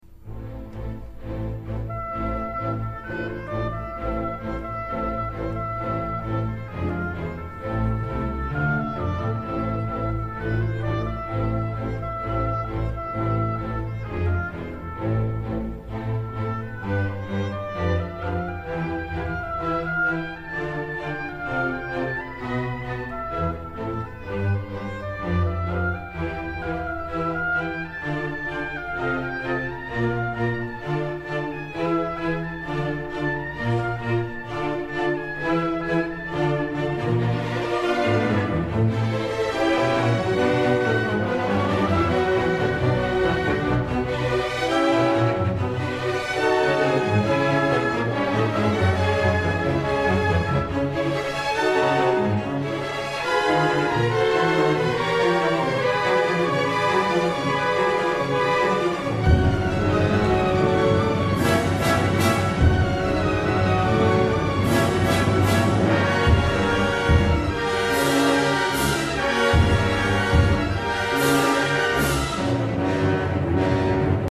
Disfruta de esta actuación
Orquesta Sinfónica